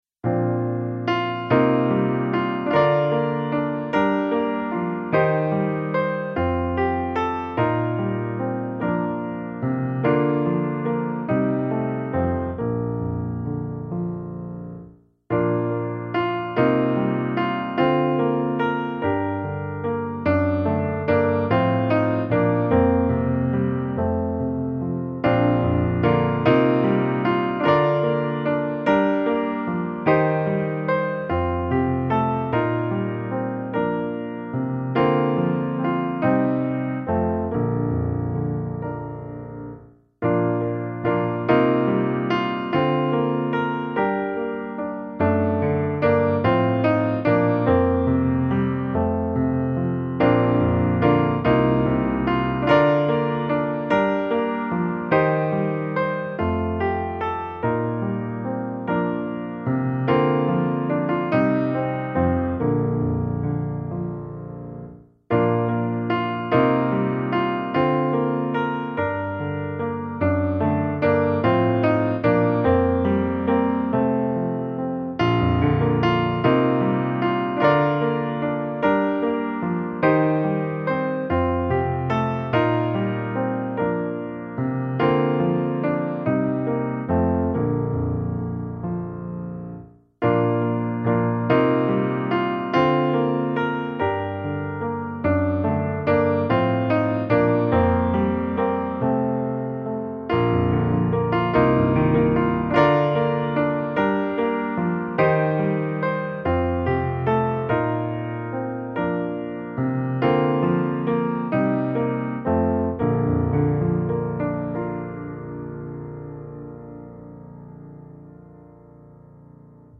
Morgon mellan fjällen - musikbakgrund